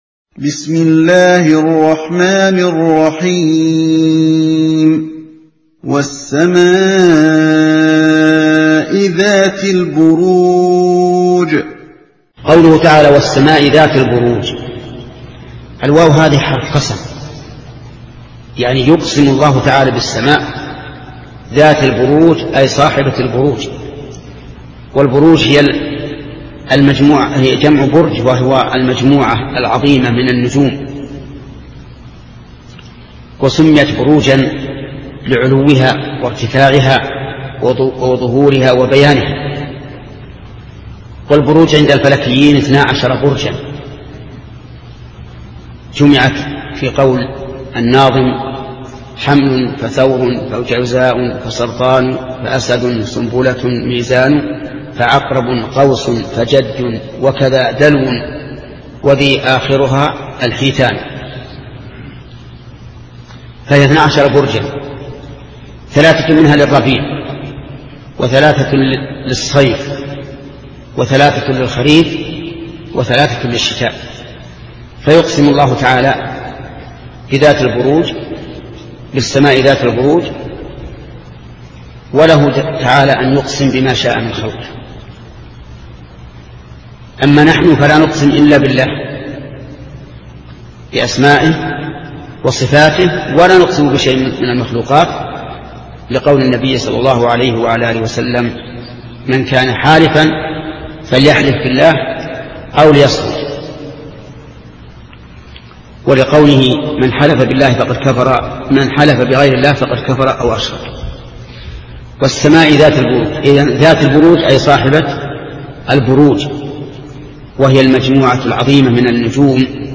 تفسير جزء عم لفضيلة الشيخ محمد صالح العثيميين